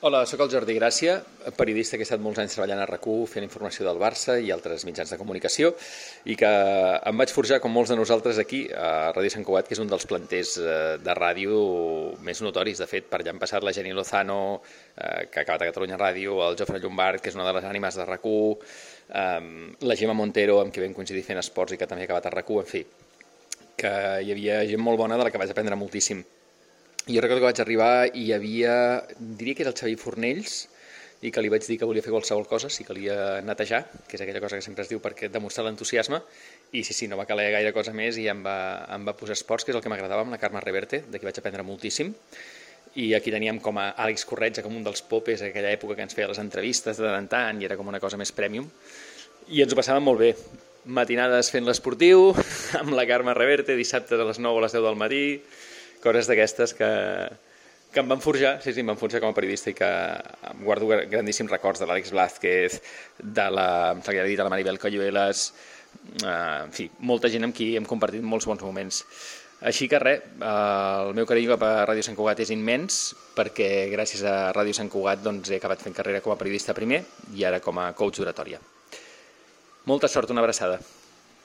Tall de veu del periodista
Divulgació